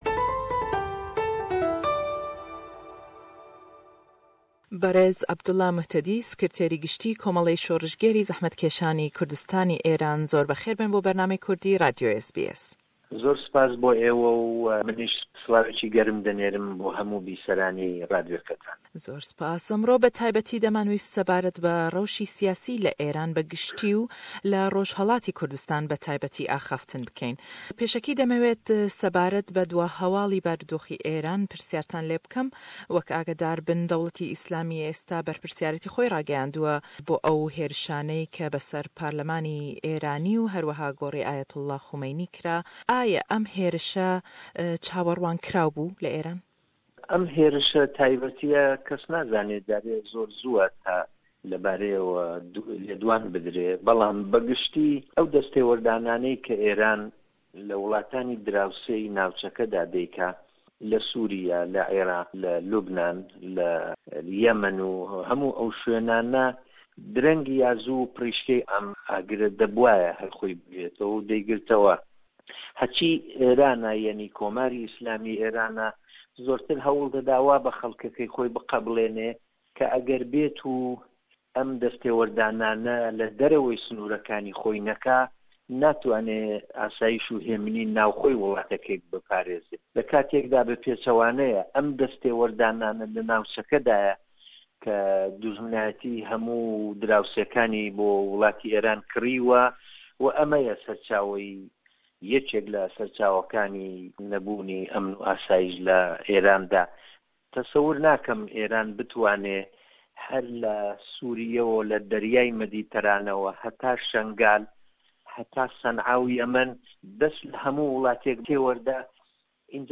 Le em lêdwane da le gell Sikirtêrî Gishtî Komełey Şorrişgêrrî Zehmetkêşanî Kurdistanî Êran, Berêz Abdullah Mohtadi, hêrishekanî Taran, rewshî siyasî le rojhellatî Kurdistan, peywendî Êran û komellgay nêwdewlletî û refrandomî serbexoyî bashûrî Kurdistan tawûtwê dekeyn.